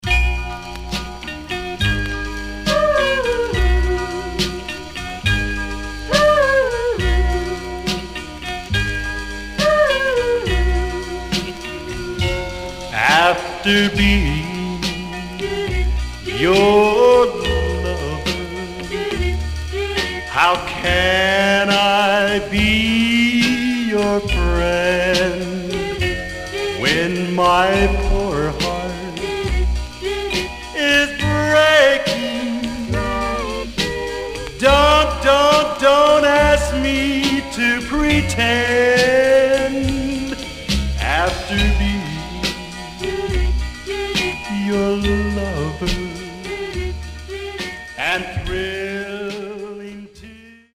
Stereo/mono Mono